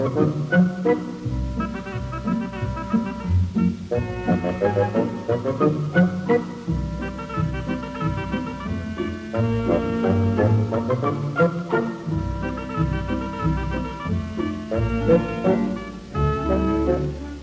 Muziek: “Dance of an ostracised imp” van Frederic Curzon
Paulus, Eucalypta & alle dieren in het bos – Jean Dulieu